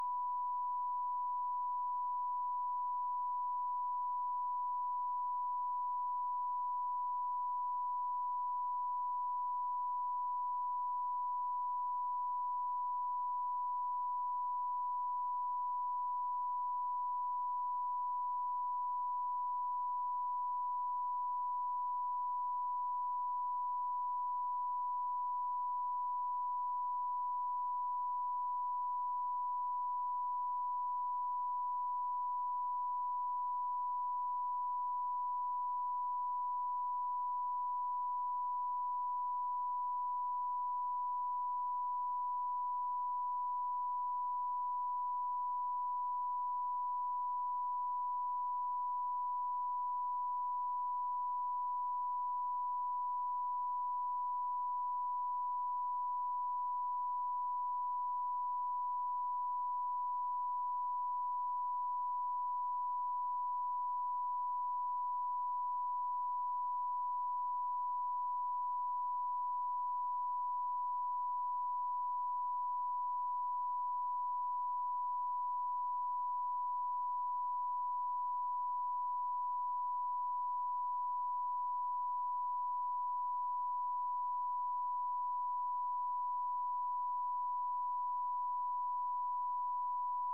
Secret White House Tapes
• President Richard M. Nixon
Location: White House Telephone
The President talked with the White House operator.
[Unintelligible]